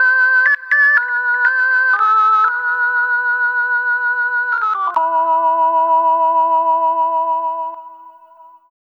Astro 2 Organ-E.wav